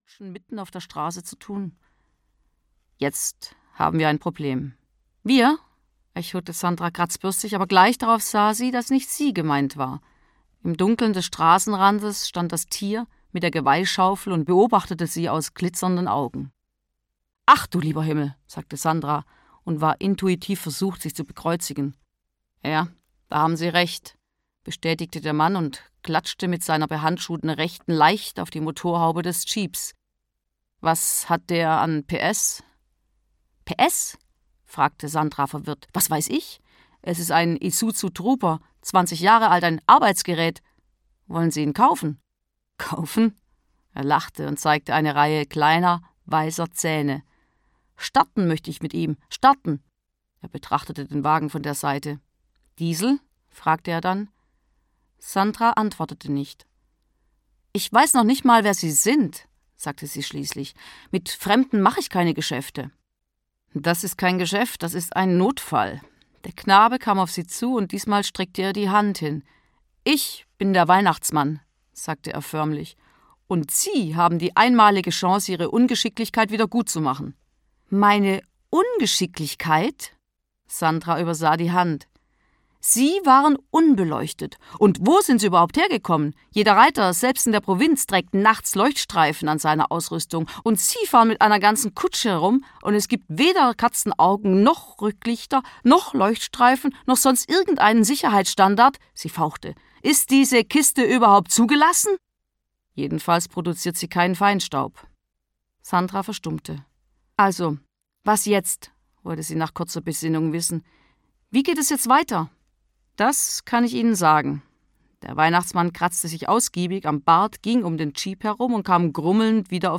Wo die Engel Weihnachten feiern - Gaby Hauptmann - Hörbuch